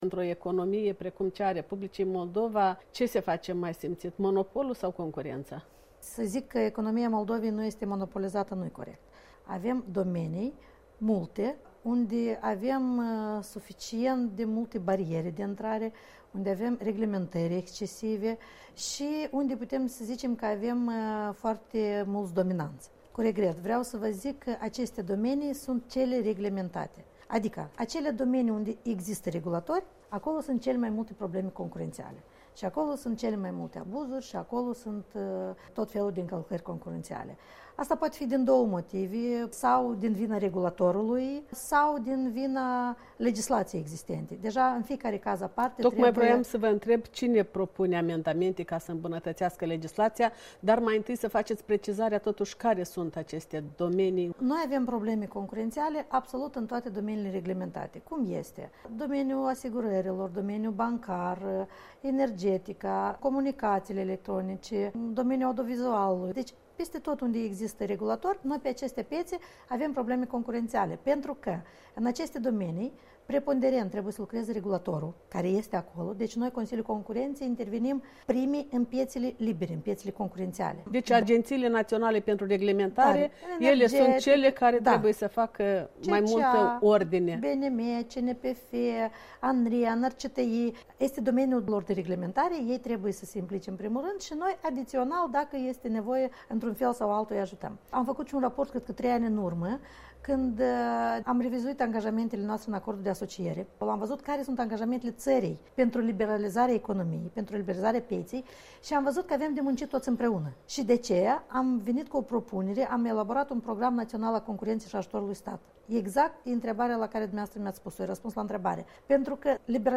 Interviu cu președintele Consiliului Concurenței din R.Moldova